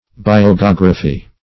biogeography - definition of biogeography - synonyms, pronunciation, spelling from Free Dictionary
Biogeography \Bi`o*ge*og"ra*phy\, n. [Gr. bi`os life + E.